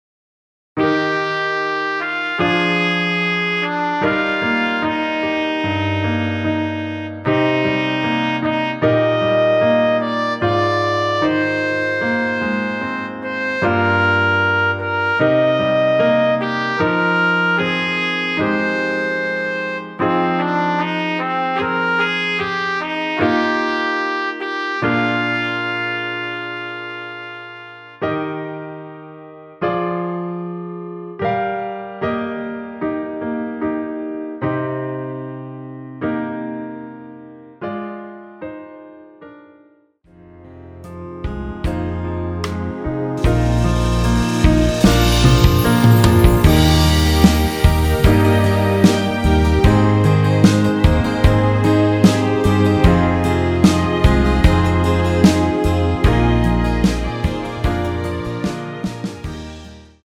원키에서(+3)올린 MR입니다.
Eb
앞부분30초, 뒷부분30초씩 편집해서 올려 드리고 있습니다.
중간에 음이 끈어지고 다시 나오는 이유는